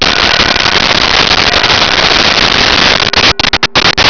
Bells005
bells005.wav